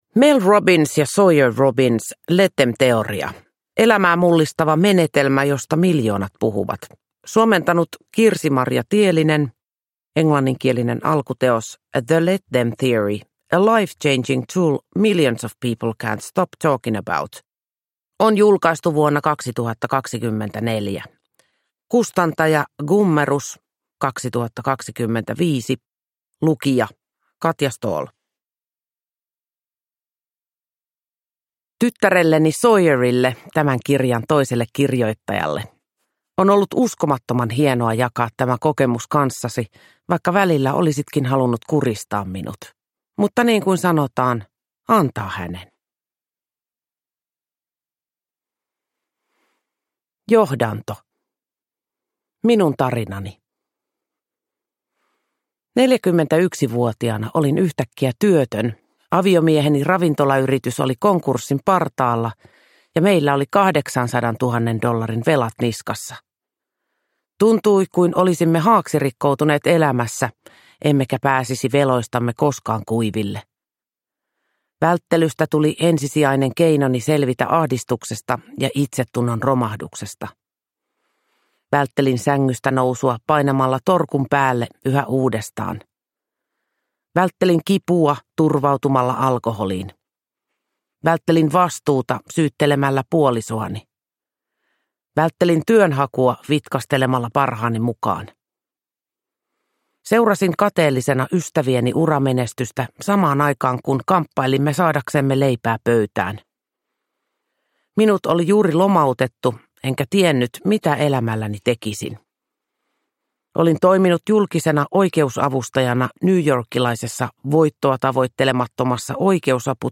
Let them -teoria (ljudbok) av Mel Robbins